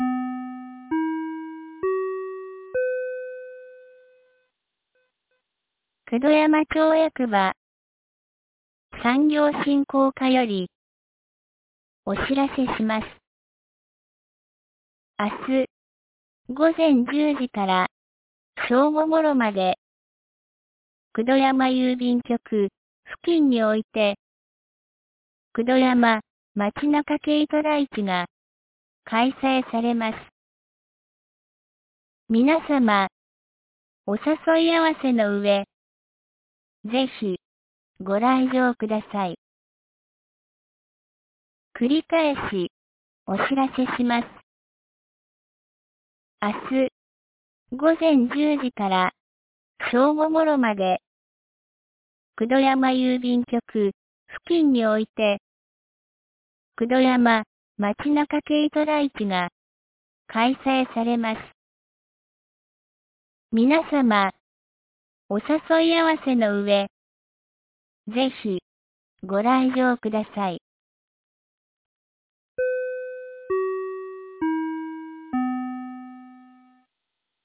2025年09月20日 16時06分に、九度山町より全地区へ放送がありました。